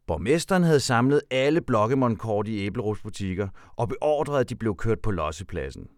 narrator_dumpintro.wav